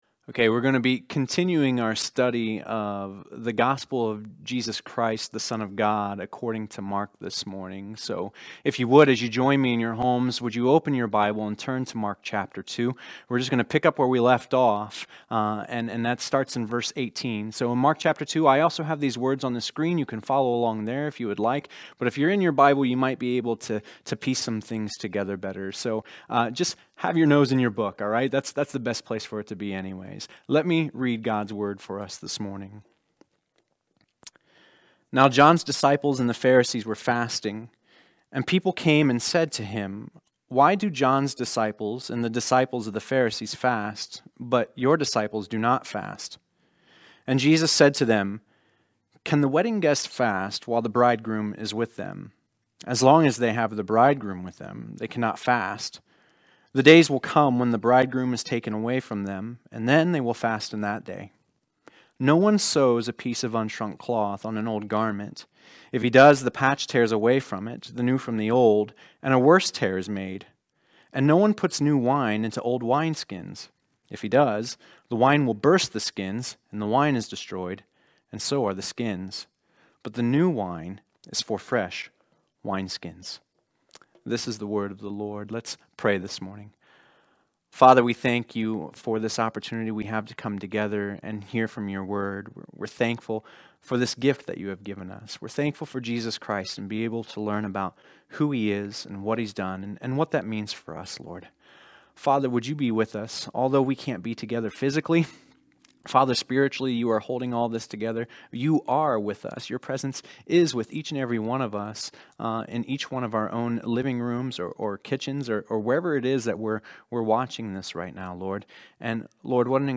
There were some recording issues during the first portion of this message. It picks up a few minutes into the sermon.